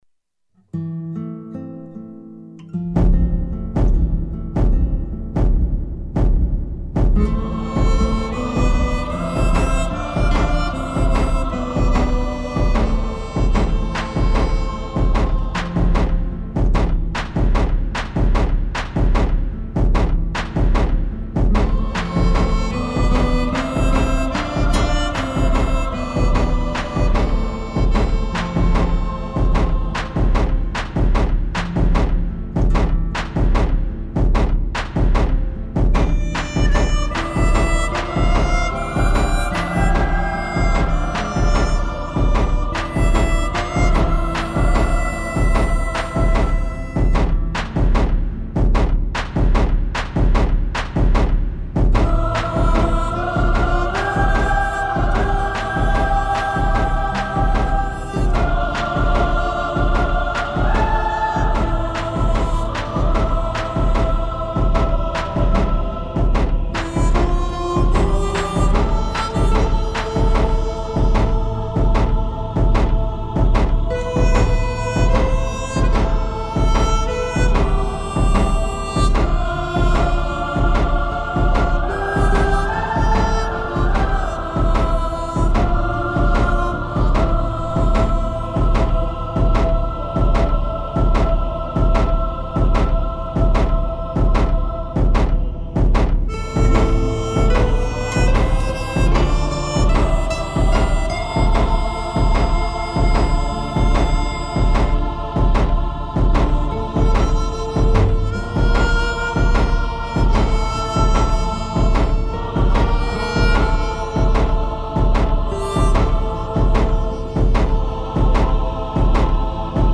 Film music